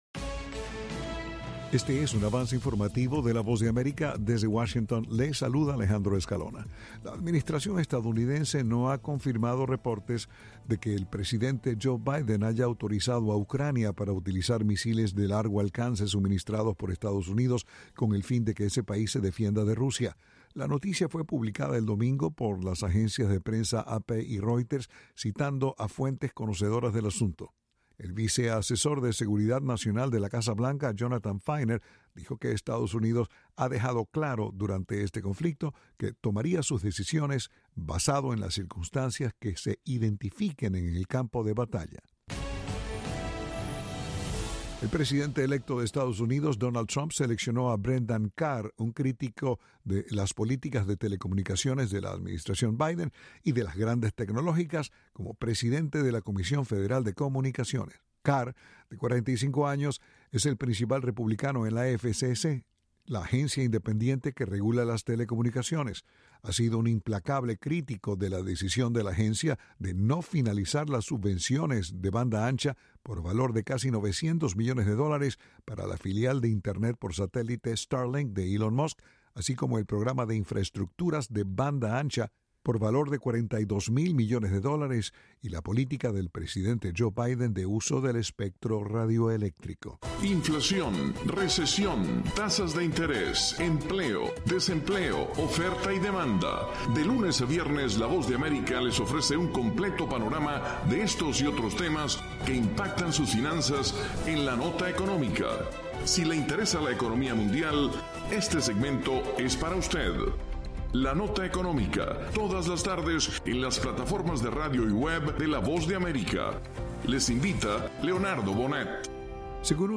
Avance Informativo
Este es un avance informativo de la Voz de América.